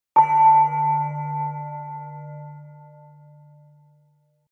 Chime-sound1.wav